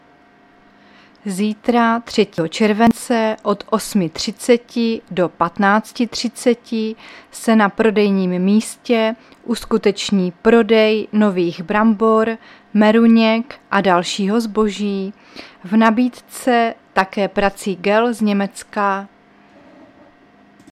Záznam hlášení místního rozhlasu 2.7.2024
Zařazení: Rozhlas